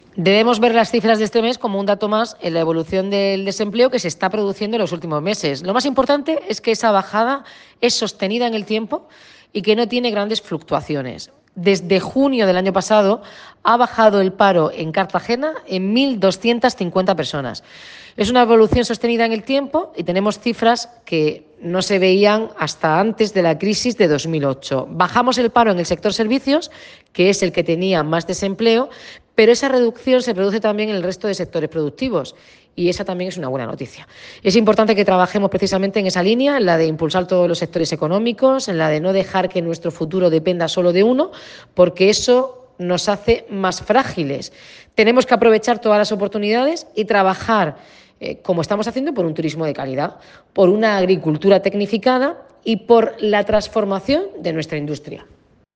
Enlace a Declaraciones de la alcaldesa, Noelia Arroyo, sobre los datos del paro